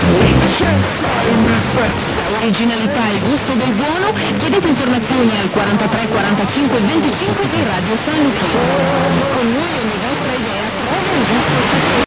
I heard that station in Finland in summer 1991 on 104.7 MHz.